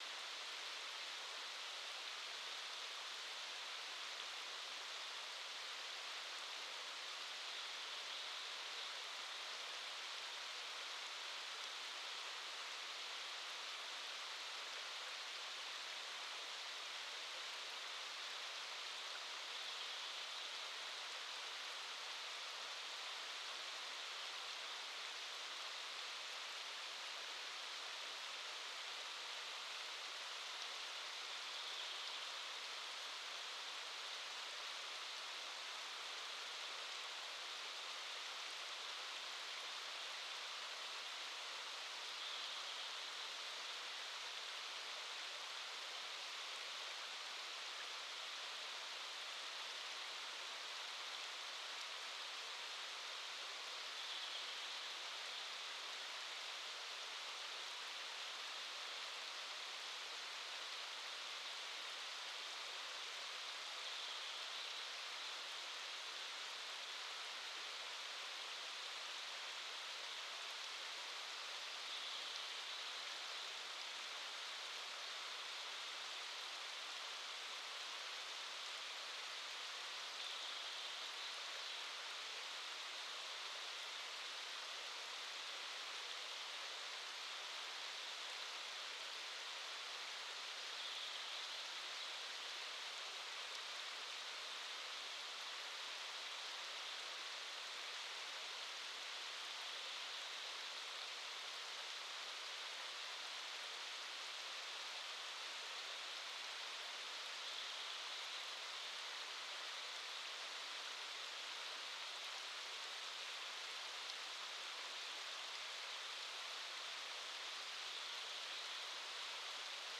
Quellrauschen4000.mp3